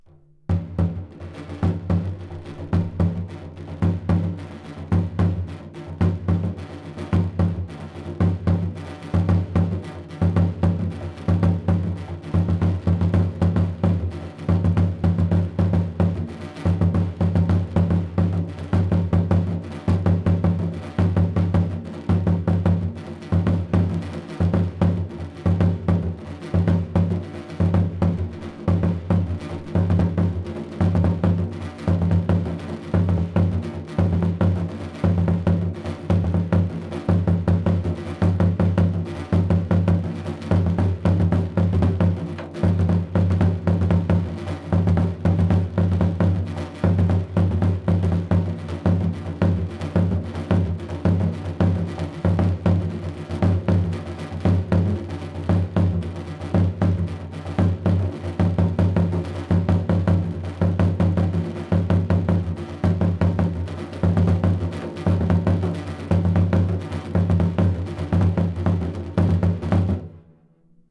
Baamaaya-Mazhe lead gung-gong drumming audio
Dagomba drumming Talking drums
African drumming